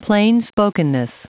Prononciation du mot plainspokenness en anglais (fichier audio)
Prononciation du mot : plainspokenness